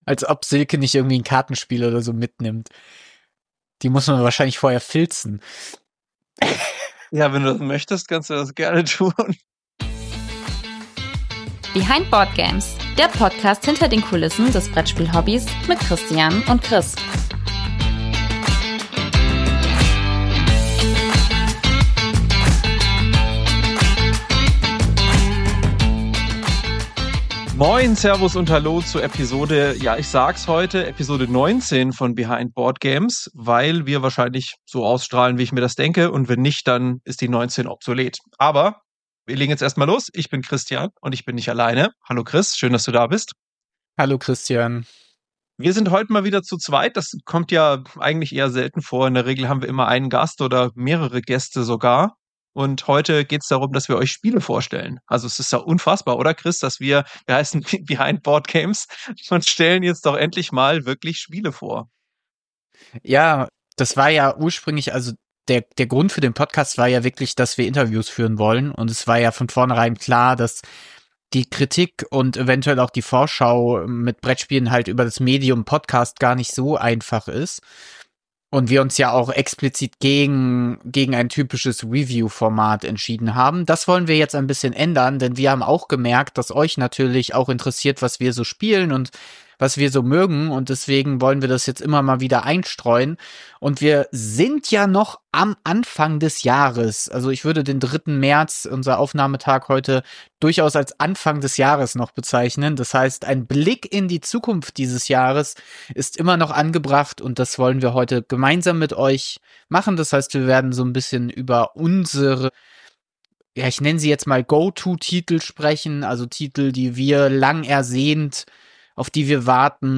Ein seltener Vorgang hat sich ergeben: Wir haben eine Episode zu zweit aufgenommen, was wir in Zukunft auch wieder etwas häufiger machen werden, ohne dabei die Interviews zu vernachlässigen.